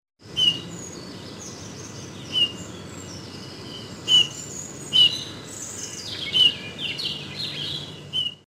Далеко вокруг разносится их негромкое мелодичное посвистывание.
Правда, песня у них неказистая, похожая на скрип.
european-bullfinch.mp3